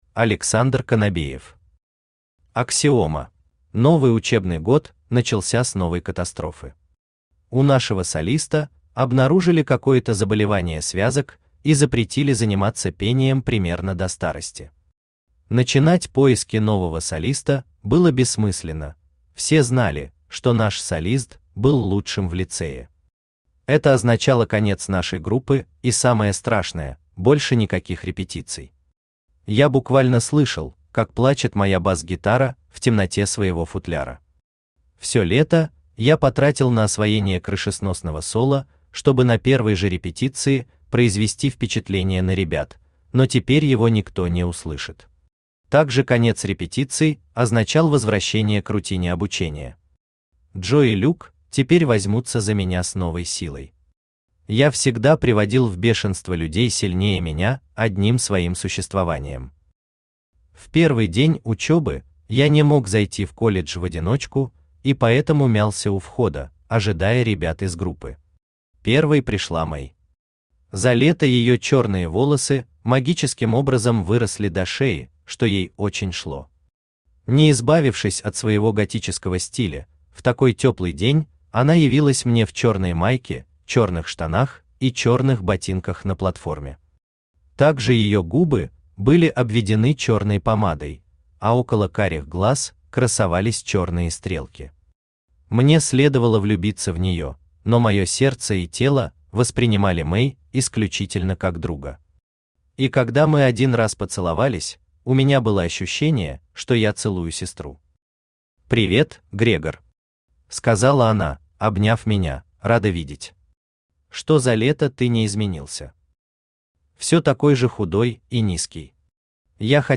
Аудиокнига Аксиома | Библиотека аудиокниг
Aудиокнига Аксиома Автор Александр Сергеевич Конобеев Читает аудиокнигу Авточтец ЛитРес.